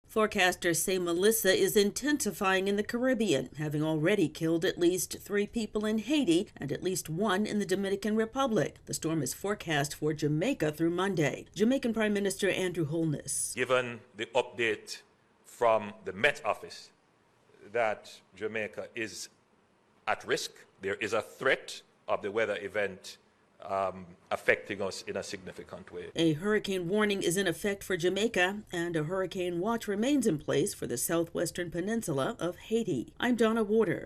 Tropical Storm Melissa is gaining strength and is expected to soon become a hurricane. AP correspondent